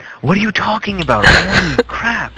Talking